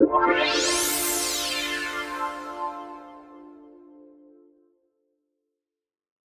Chords_D_03.wav